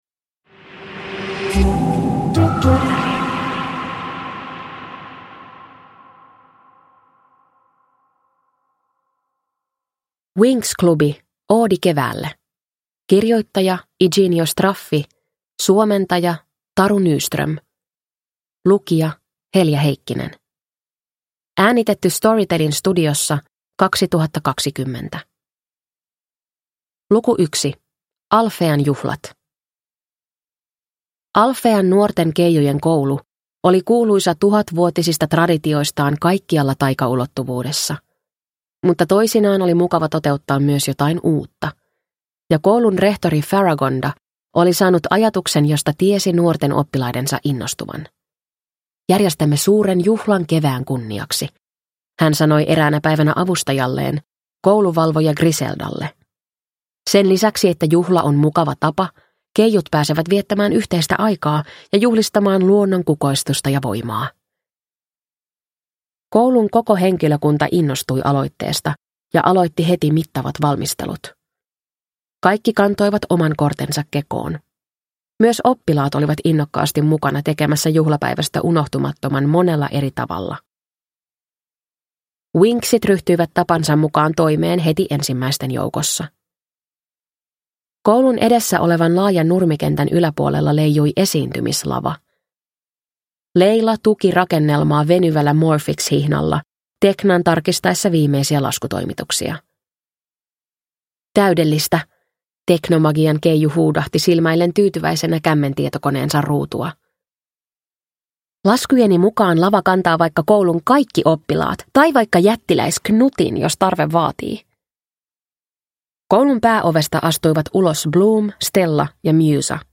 Winx - Oodi keväälle – Ljudbok